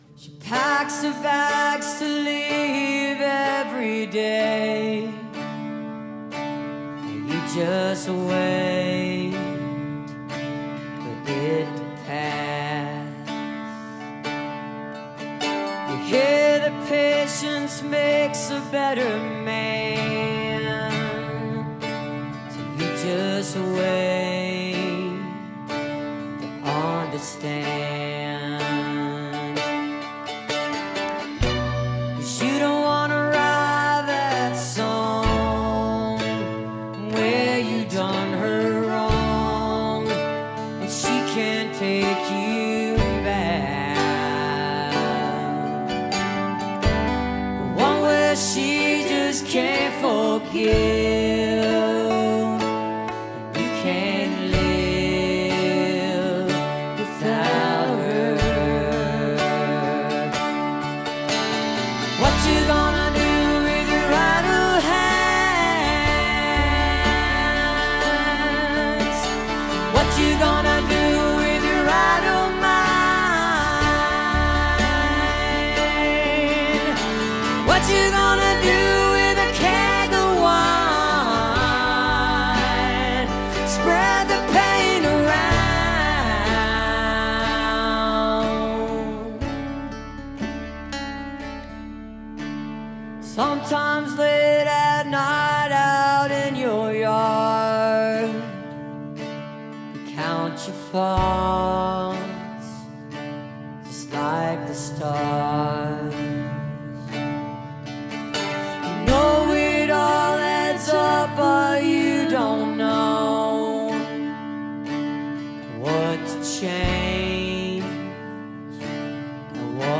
(recorded from the webcast)